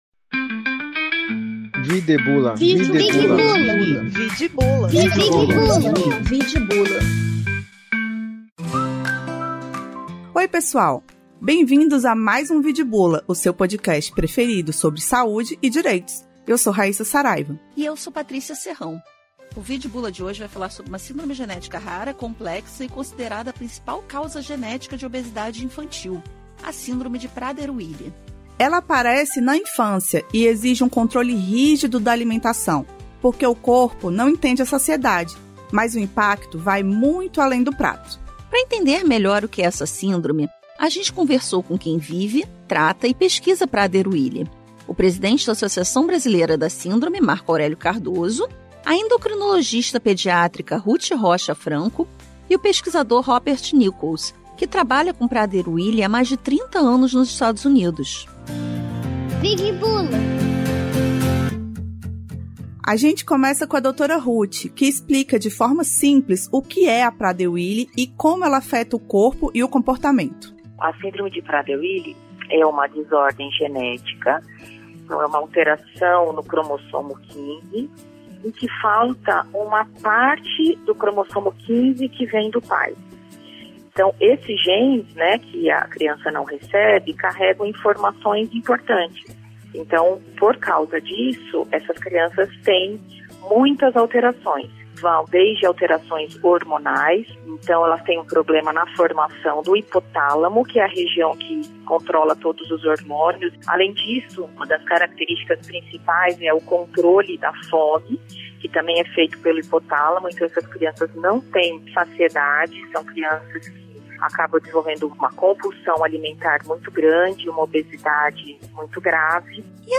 E para entender melhor o que é essa síndrome entrevistamos com quem vive, trata e pesquisa Prader-Willi